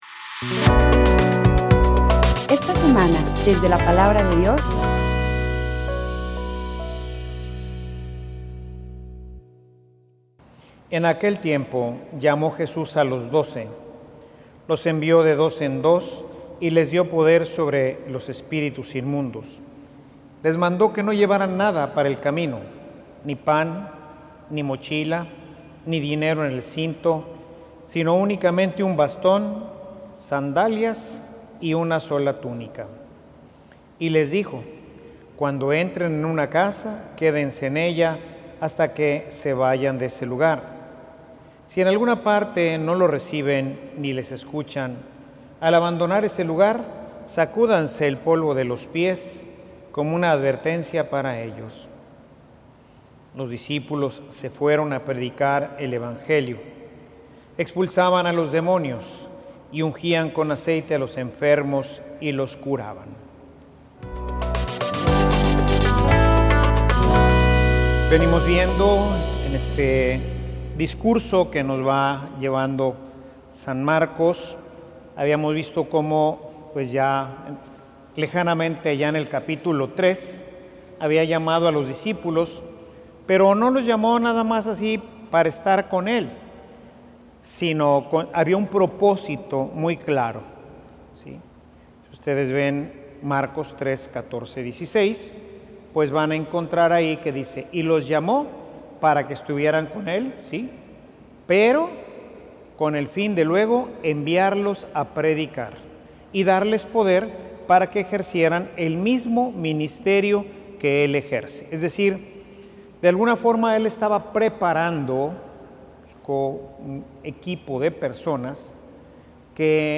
Homilia_Somos_propietarios_o_somos_extranos.mp3